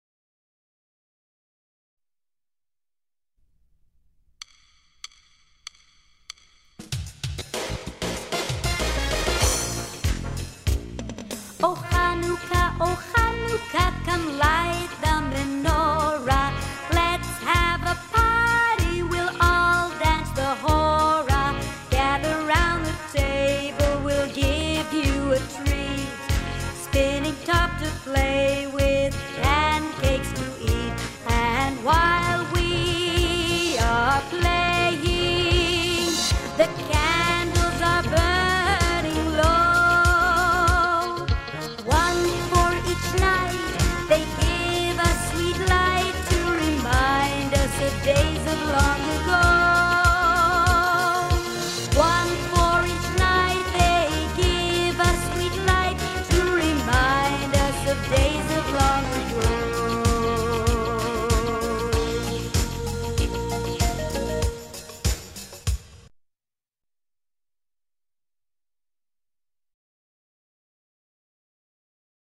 Oh Hanukkah: boys |